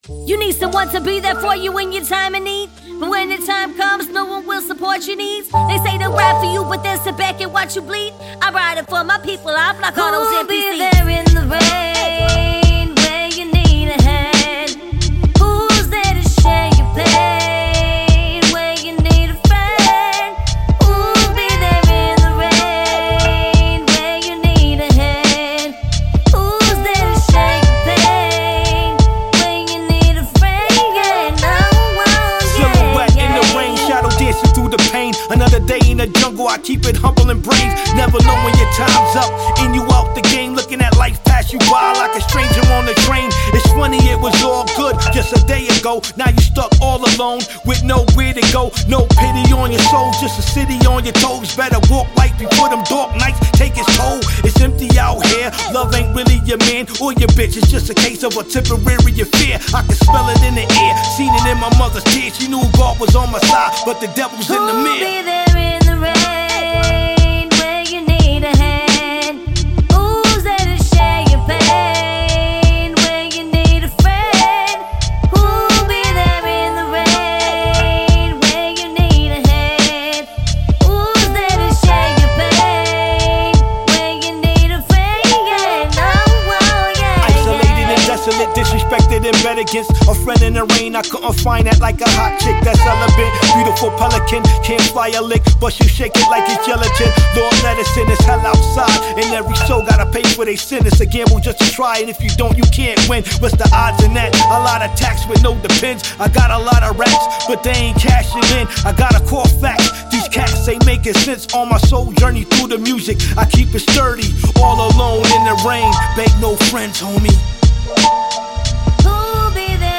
Created 2025-03-02 15:52:48 Hip hop 0 ratings